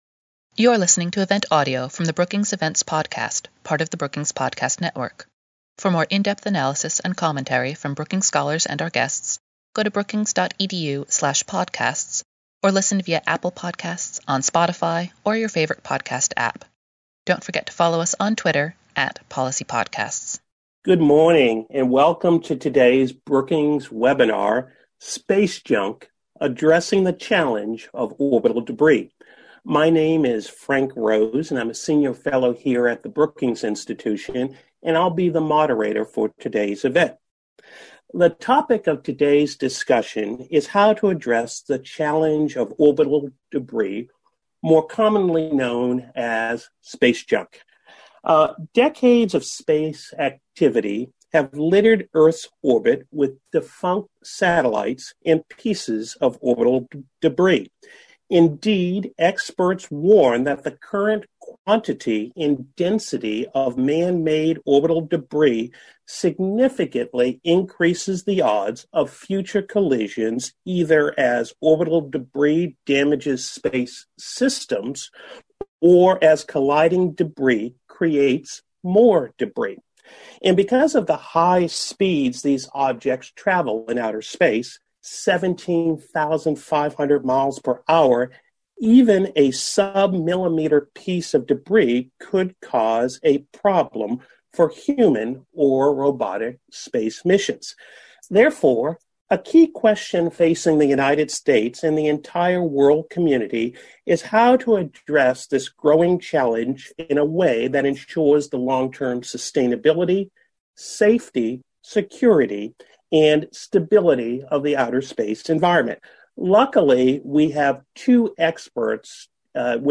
Webinar: Space junk—Addressing the orbital debris challenge | Brookings
Senior Fellow Frank A. Rose moderated the discussion.